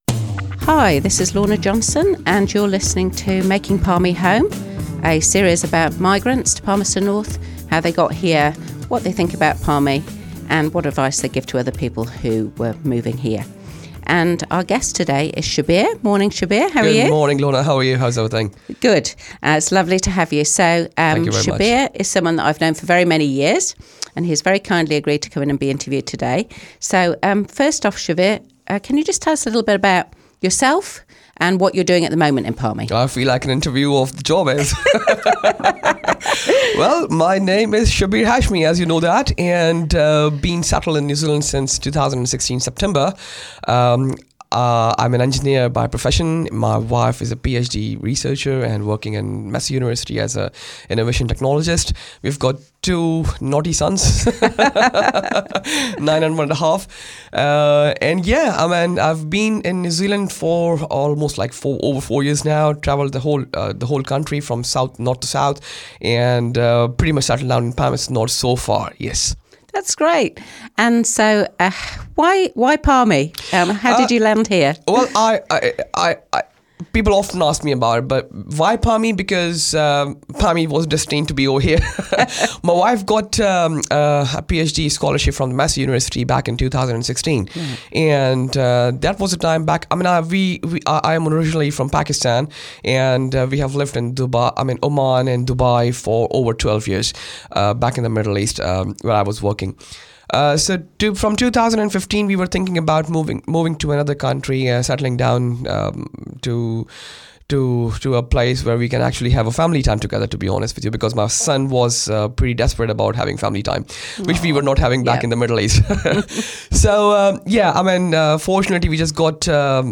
Palmerston North City Councillor Lorna Johnson hosts "Making Palmy Home", a Manawatū People's Radio series interviewing migrants about their journeys to Palmerston North.
interview
radio